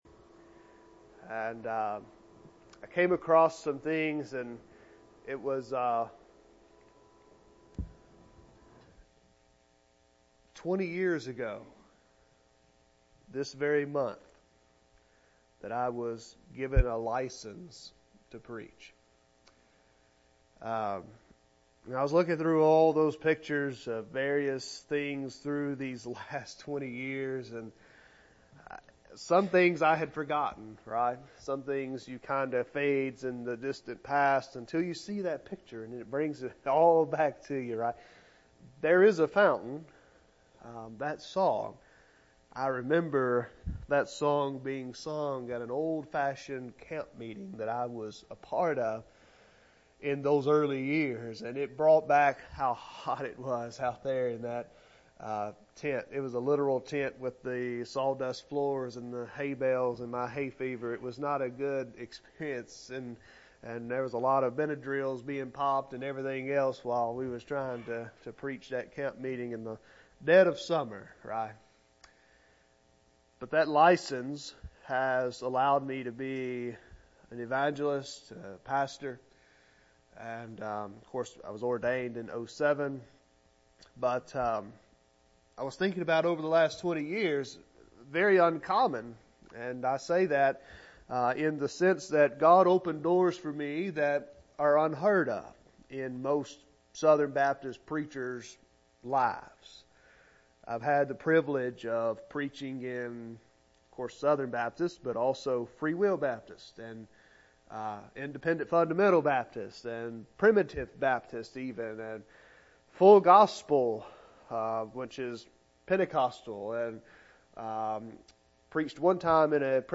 Sermons | West Acres Baptist Church